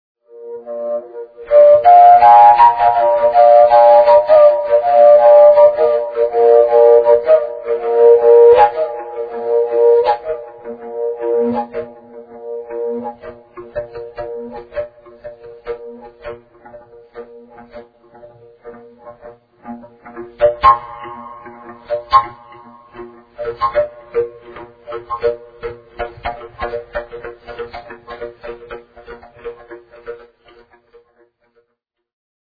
For those, who are not "in the picture" - Fujara is Slovak traditional wood instrument (a thing looking like a "big pipe"), with characteristic, unreplaceable sound. Here are my fujara´s improvisations with different musicians, alone, live, studio, etc...
Playing: Me (fujara) [2002].